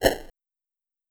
Retro Swooosh 07.wav